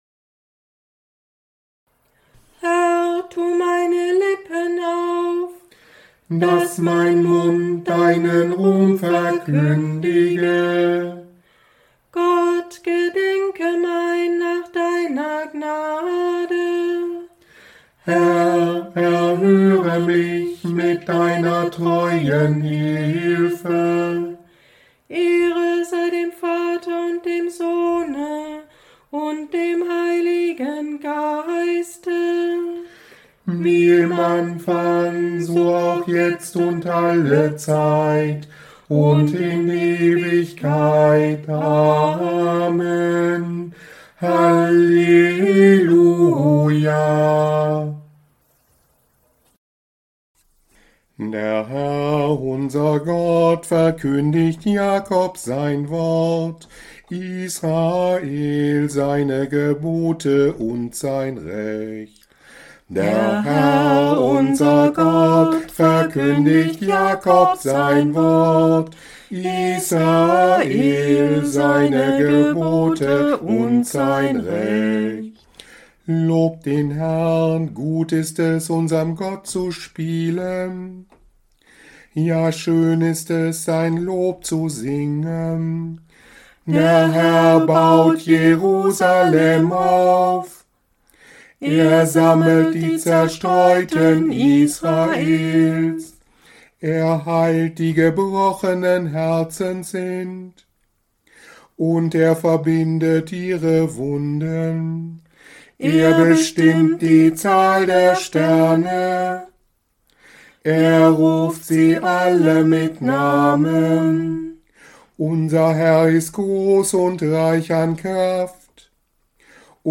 Morgengebet am Montag nach dem 2. Sonntag nach dem Christfest 2026 (5. Januar) Nummern im Tagzeitenbuch: 330, 403, 405, 409, 410, 433+222, Raum zum persönlichen Gebet, 13.1